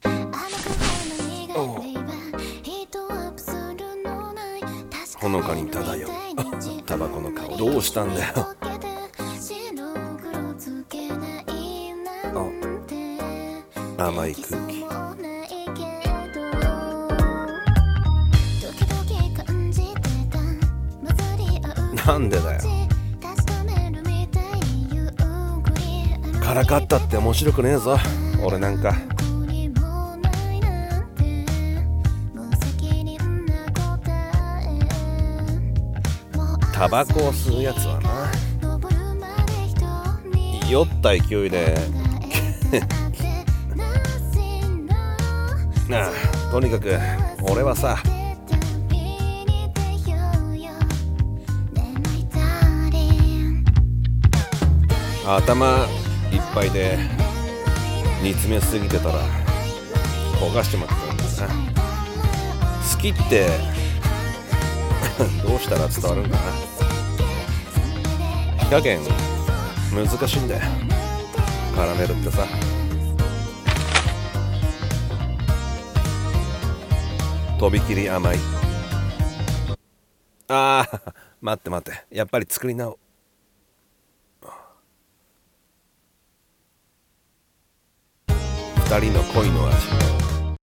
【声劇】こがれる、きゃらめりぜ。